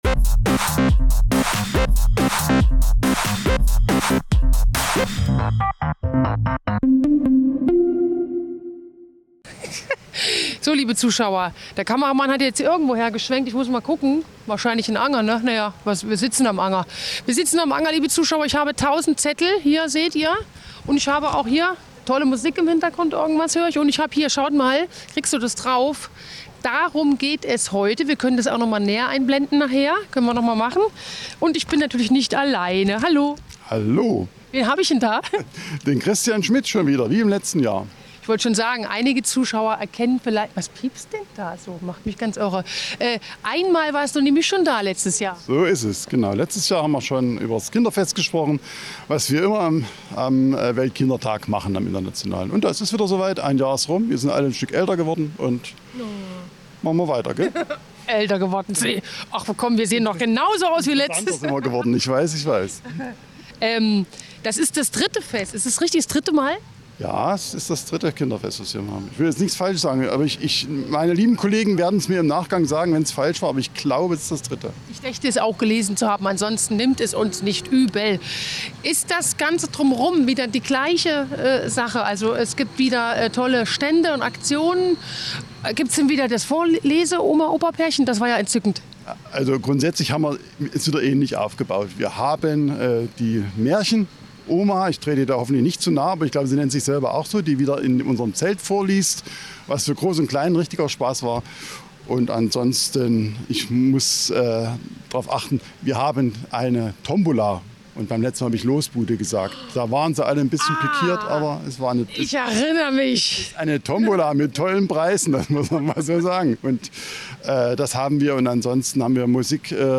Unser Team war in der Jugendstrafanstalt Ichtershausen.